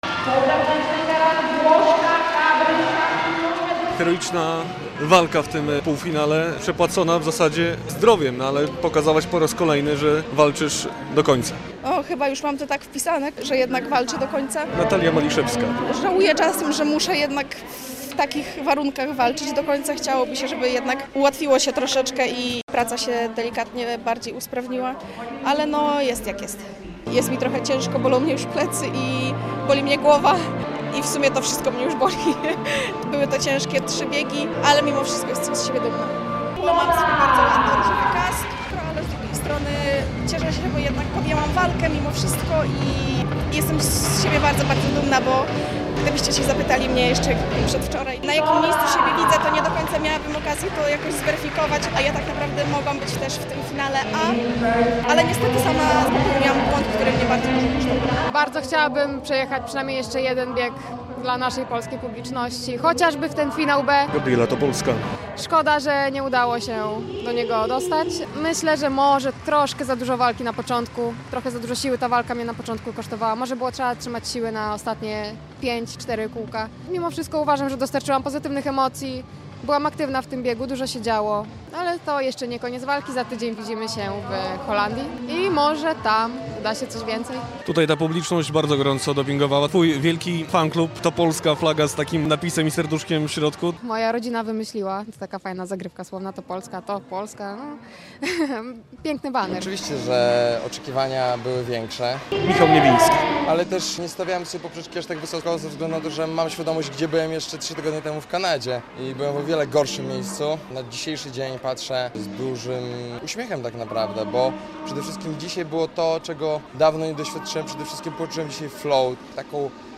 World Tour w shorttracku w gdańskiej Olivii - relacja